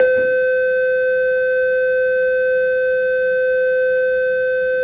modern_school_bell.wav